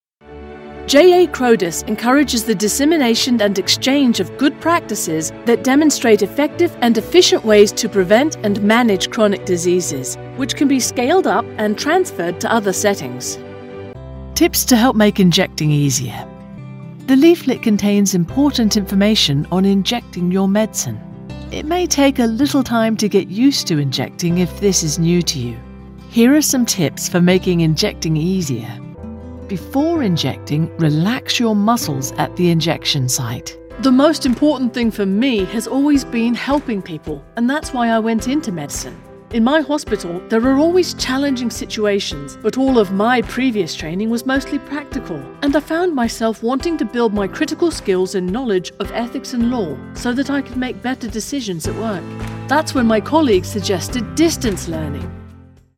Medical Narration
I'm a voice actor with a native British accent.
- Recording booth: Whisperroom 5x5
- Microphones: Neumann TLM-103
Contralto
ConversationalFriendlyAuthoritativeWarmExperiencedAssuredEngagingTrustworthyConfidentRelatable